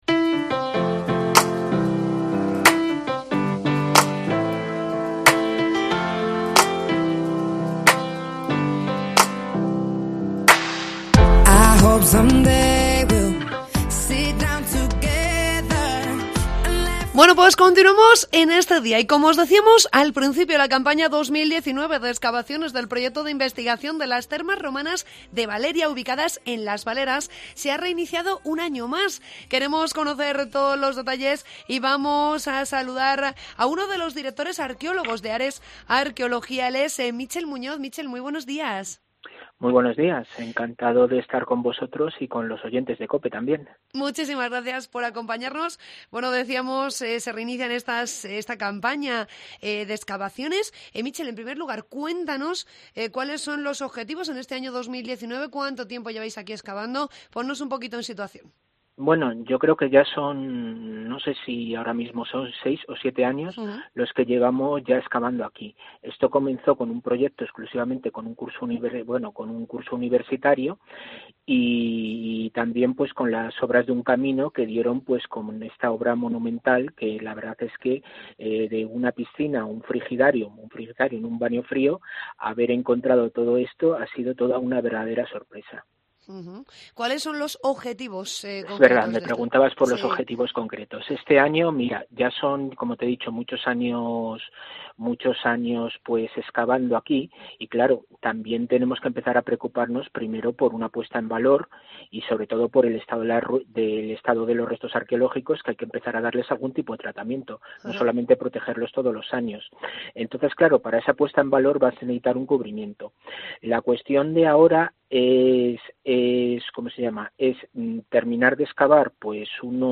AUDIO: Escucha la entrevista con uno de los directores de Ares Arqueología